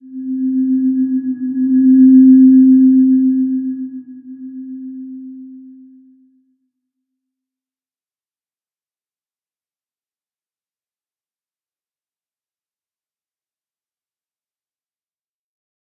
Slow-Distant-Chime-C4-p.wav